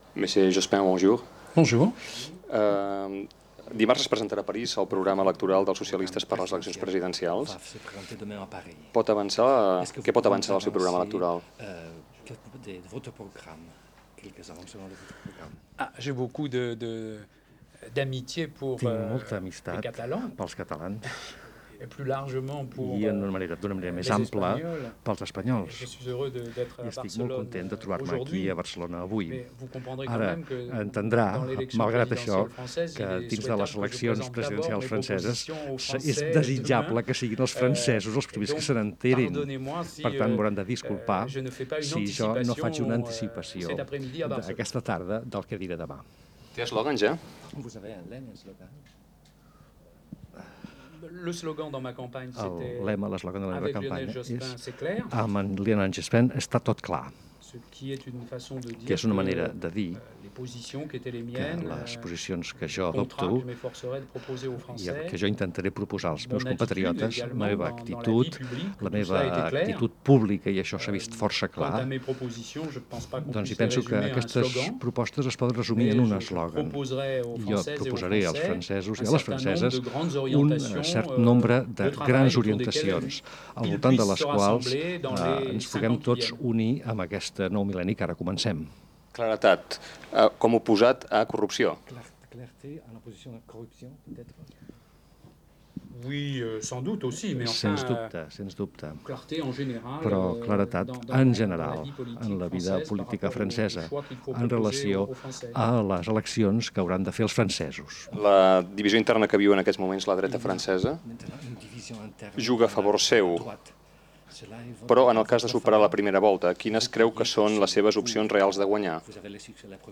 Entrevista, amb traductor, a Lionel Jospin per conèixer el programa electoral dels socialistes francesos per les eleccions presidencials.
Info-entreteniment
Fragment extret de diferents cintes trobades a Catalunya Ràdio.